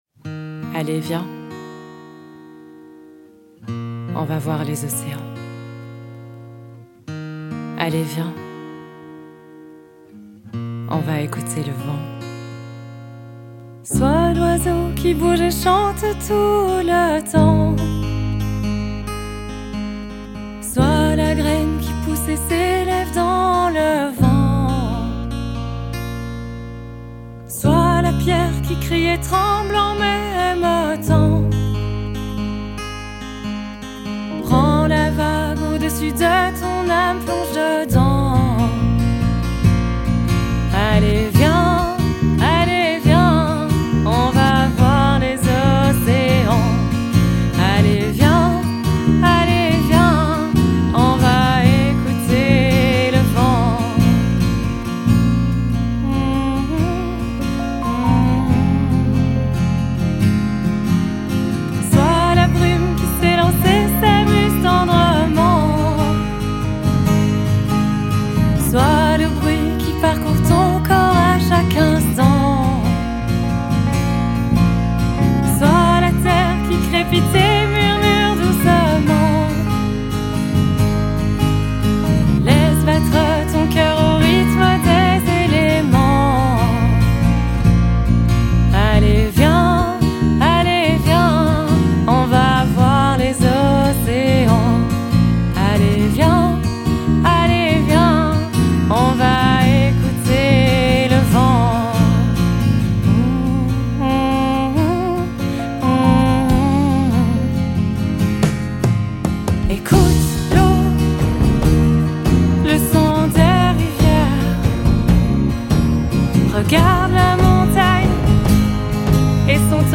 C’est une chanson calme, douce et apaisante.